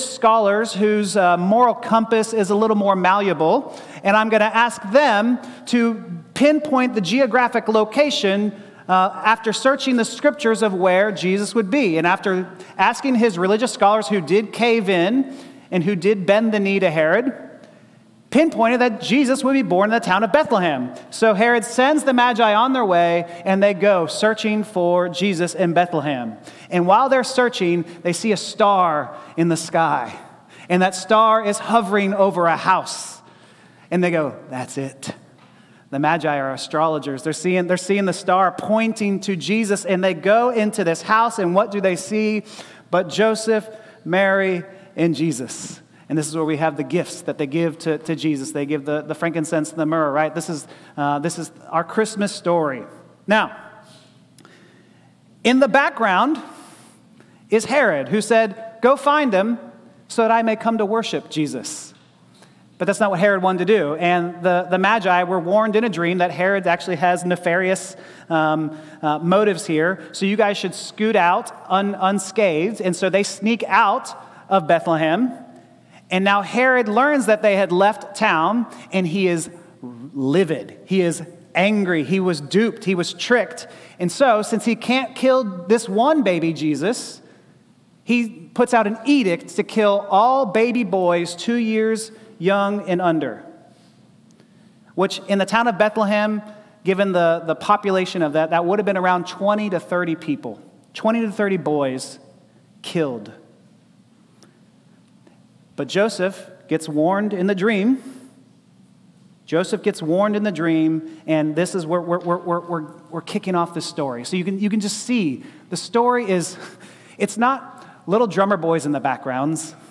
10AM Service Dec 28th 2025